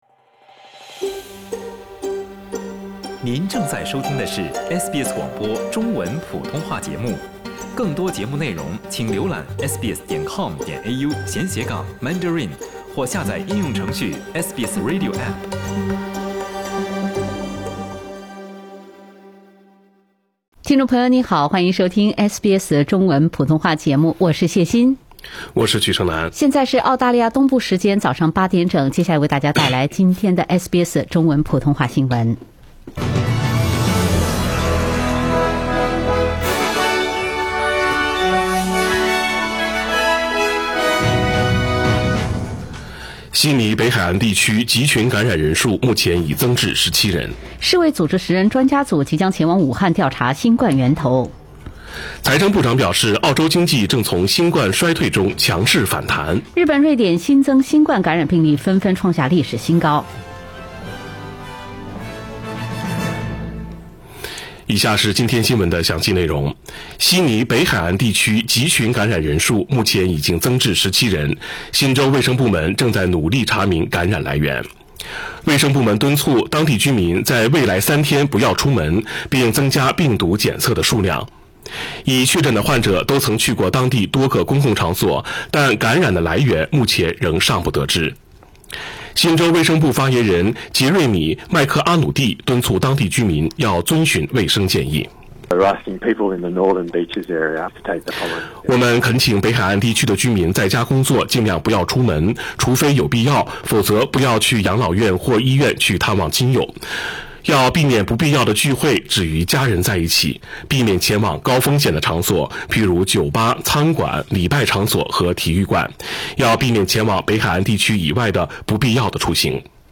SBS早新聞（12月18日）